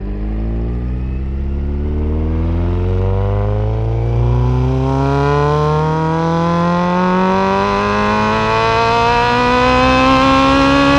rr3-assets/files/.depot/audio/Vehicles/i4_04/i4_04_accel.wav